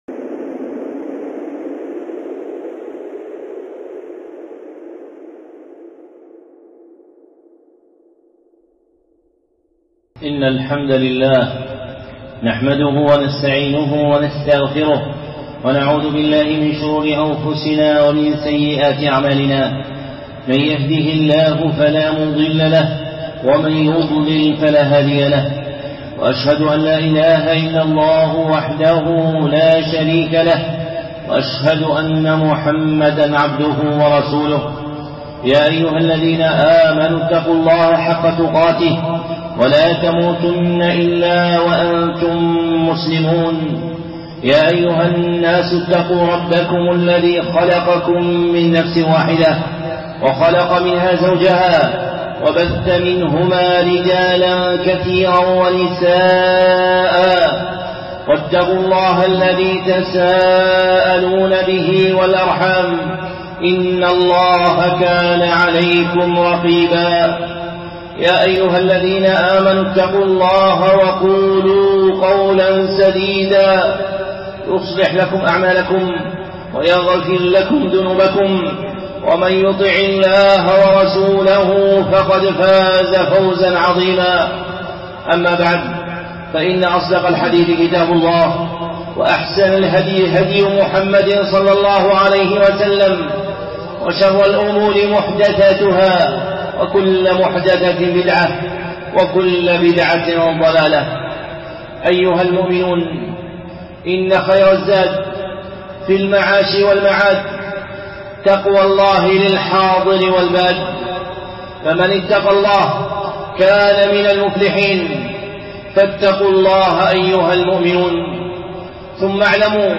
خطبة (معبود الموحدين) الشيخ صالح العصيمي